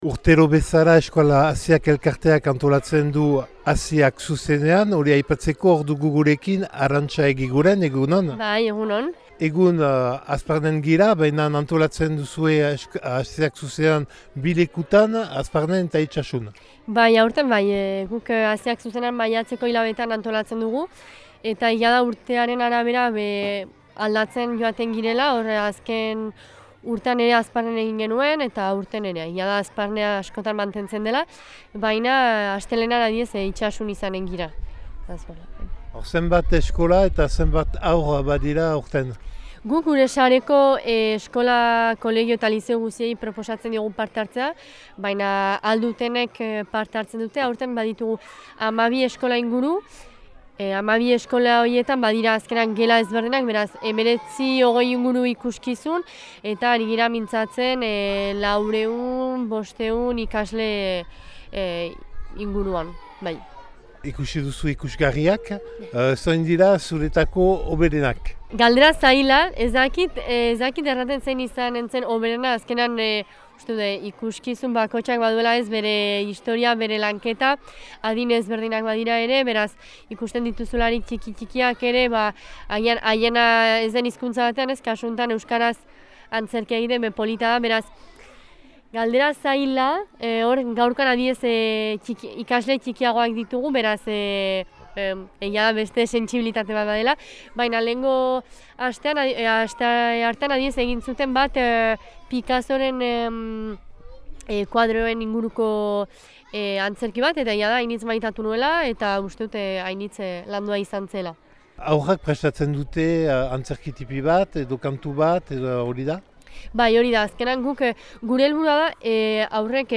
Euskal Haziak elkarteak haurrentzako euskaraz bizi ikuskizunaren inguruko astea antolatzen du urtero. Huna hemen 2025eko maiatzaren 16an Hazparnen egindako erreportaia.